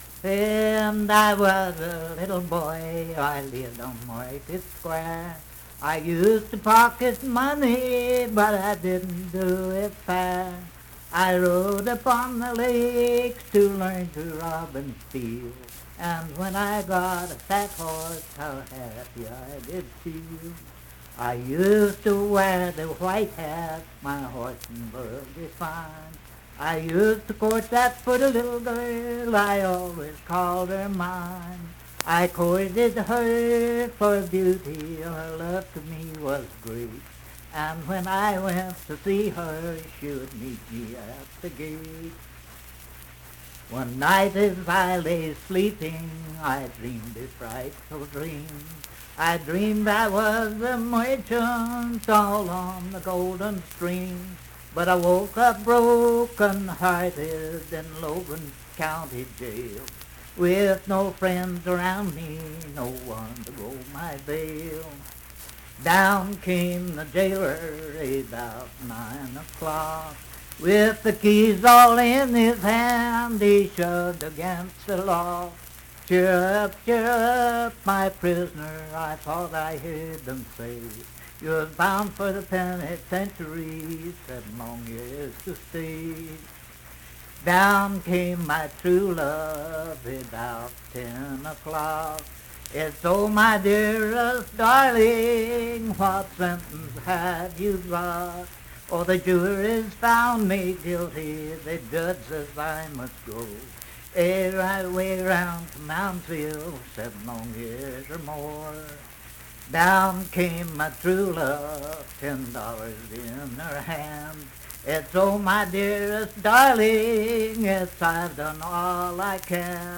Unaccompanied vocal music
Verse-refrain 8d(4).
Voice (sung)
Huntington (W. Va.), Cabell County (W. Va.)